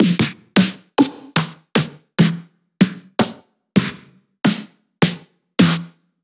001_Snares_Synth Snares2.wav